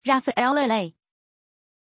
Pronunția numelui Raphaëlla
pronunciation-raphaëlla-zh.mp3